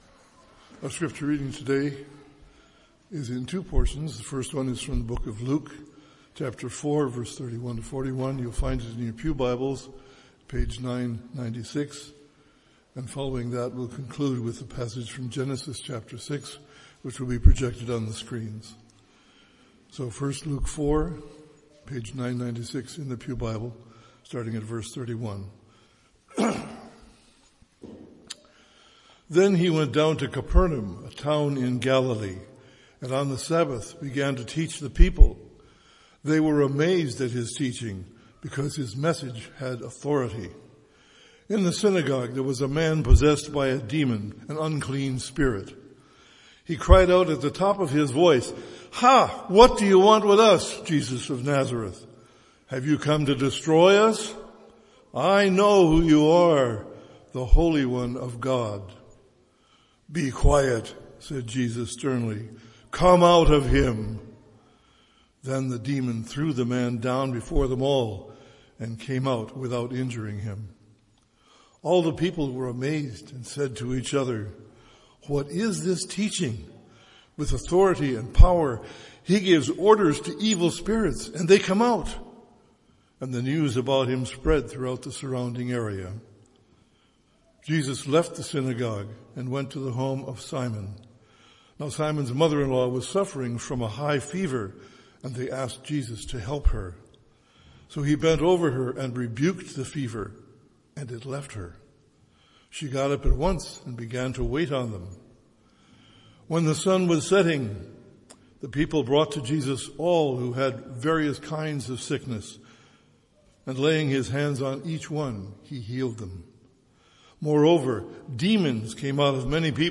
with a reading of Holy Scripture and prayer
MP3 File Size: 27.6 MB Listen to Sermon: Download/Play Sermon MP3